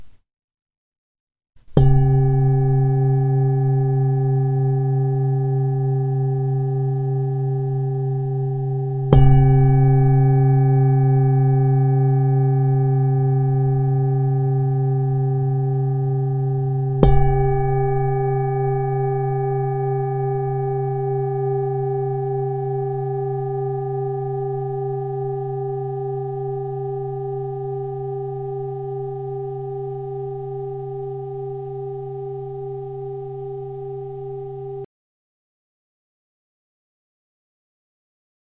Lesklá tibetská mísa C3 26cm
Nahrávka mísy úderovou paličkou:
Jde o ručně tepanou tibetskou zpívající mísu dovezenou z Nepálu.
Tahle mísa zní v tónu kořenové čakry.